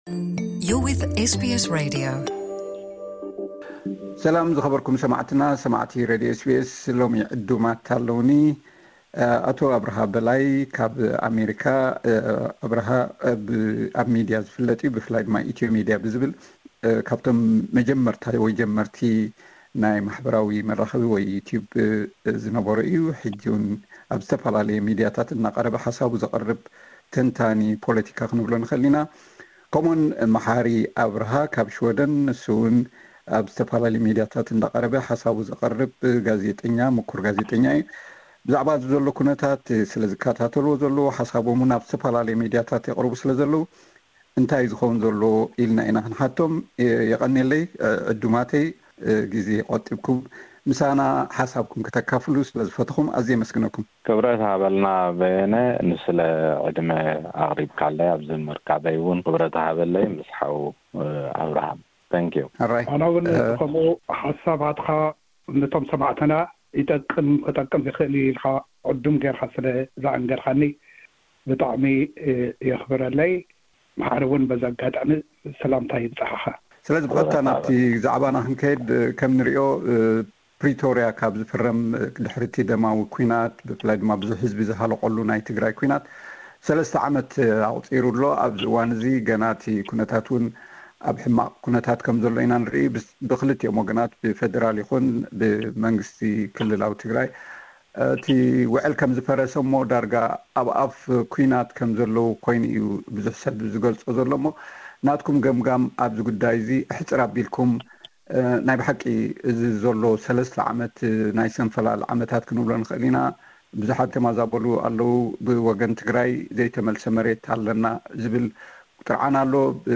እዚ ኩነታት ክንዮ ኢትዮጵያን ኤርትራን ካልኦት ሰብ ረብሓ ዝኾኑ ሓይልታት'ውን ከይሳተፍዎ ተሰጊኡ ይርከብ። ብዛዕባ ዉዕል ፕሪቶሪያ ከይትግበር ዝዕንቅጽ ዘሎ መን'ዩ፧ ሕጋውነት ሕቶ ባሕሪ ኢትዮጵያን ዘስዕቦ ጂኦ ፖሎቲካዊ ቅልውላው ዝምልከት ሰፊሕ ዘተ ቀሪቡ ኣሎ።